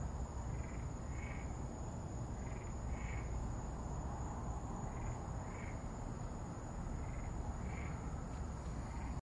描述：从我的车上录制了一个现场，背景是我的冷却发动机或我另一边的变电站的声音。
Tag: 场记录 鸟类 昆虫